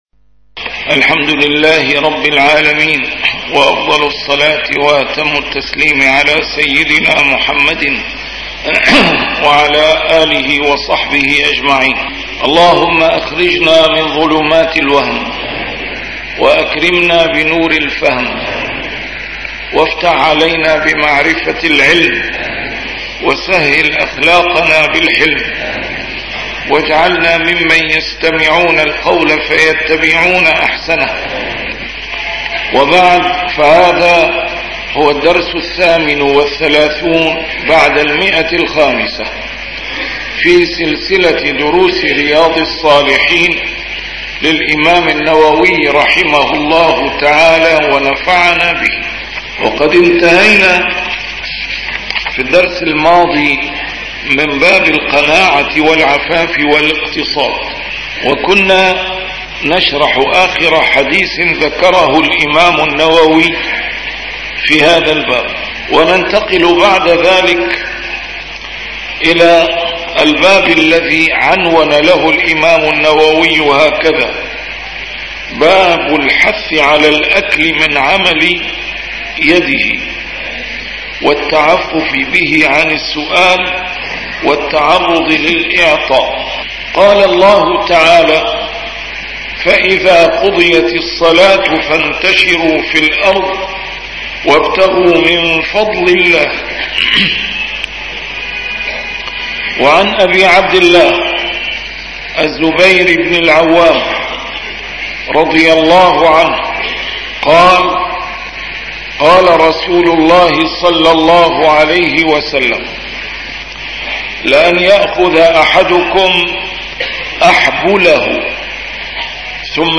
A MARTYR SCHOLAR: IMAM MUHAMMAD SAEED RAMADAN AL-BOUTI - الدروس العلمية - شرح كتاب رياض الصالحين - 538- شرح رياض الصالحين: الحث على الأكل من عمل يده